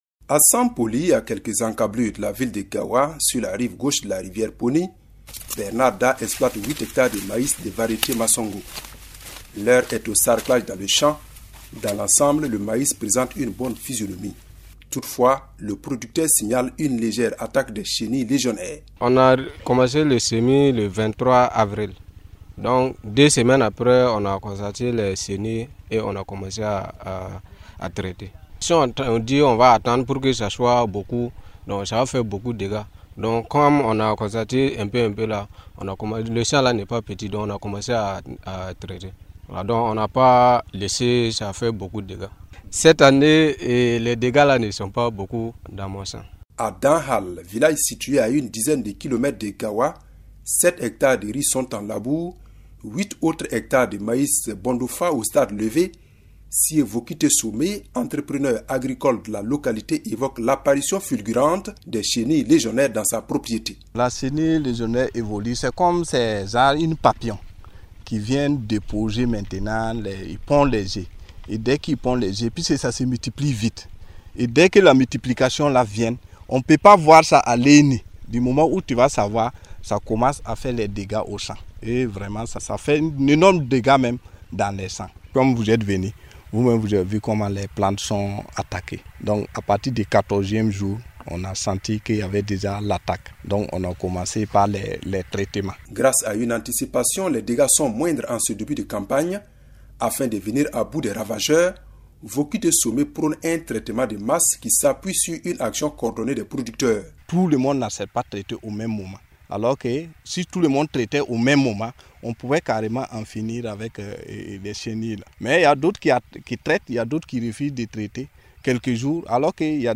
Dans la commune de Gaoua, les champs de maïs dans les bas-fonds présentent une bonne mine en ce début de campagne agricole. Malheureusement, certains producteurs évoquent quelques attaques légères de chenilles légionnaires depuis environ deux semaines. Le constat a été fait vendredi dernier à Sampoli et à Danhal village situé à 11km de Gaoua.